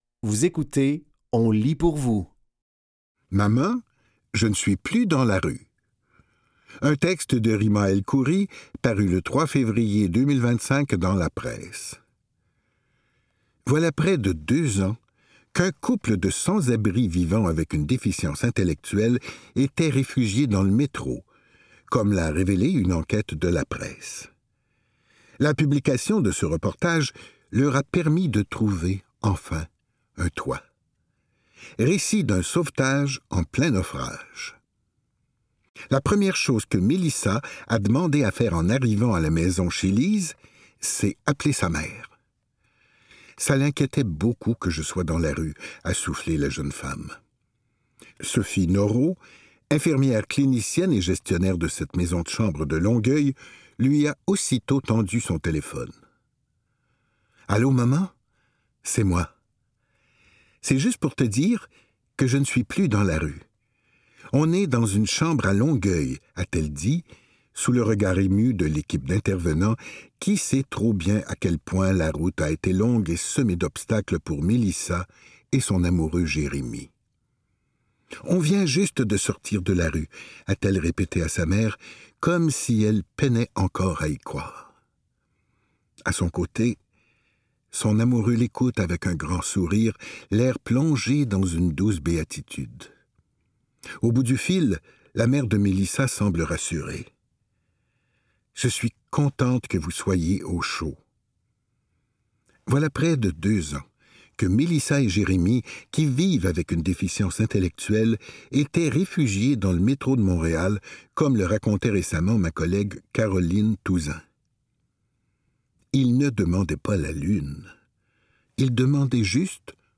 Dans cet épisode de On lit pour vous, nous vous offrons une sélection de textes tirés des médias suivants : La Presse et Le Journal de Montréal.